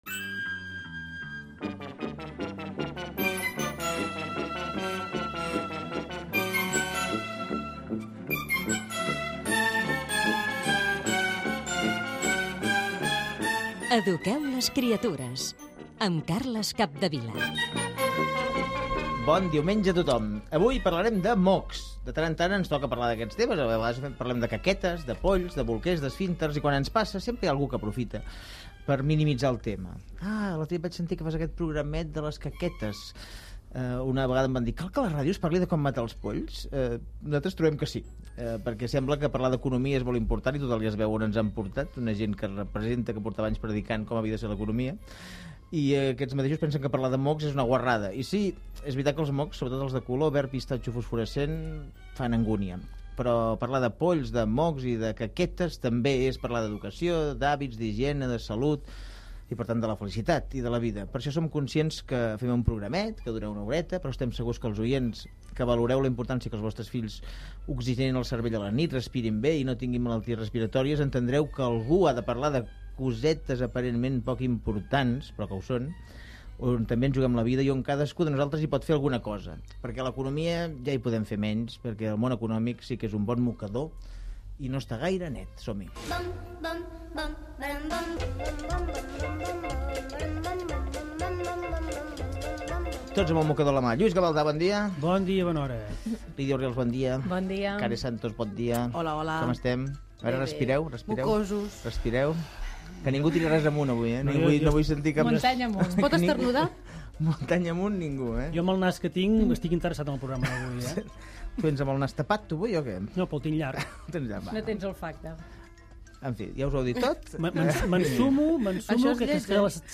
Careta del programa
Divulgació